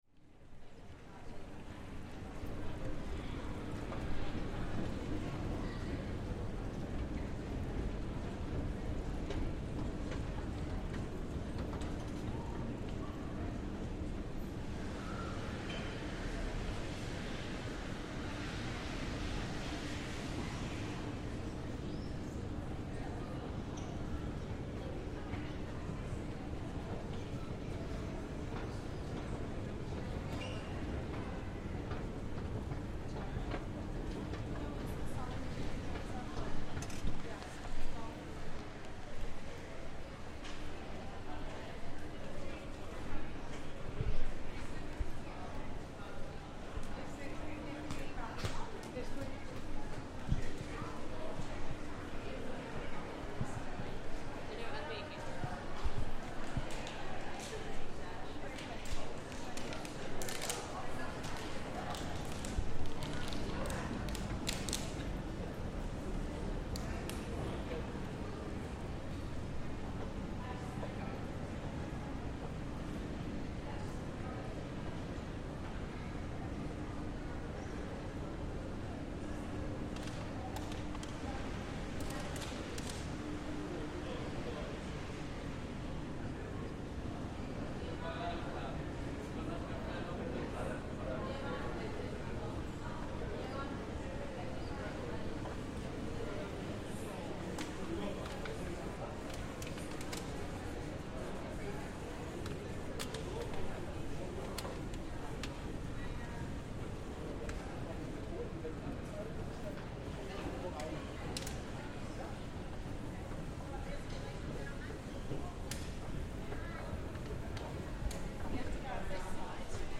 In the turbine hall